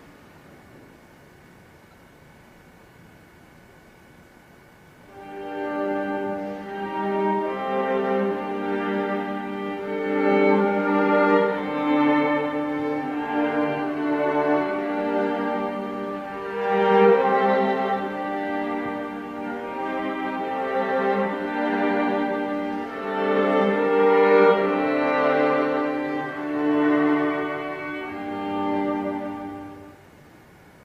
주제는 G단조의 장송 행진곡과 같으며, G장조 화음으로 끝맺는다.
주제G단조의 장송 행진곡, G장조 화음으로 끝맺음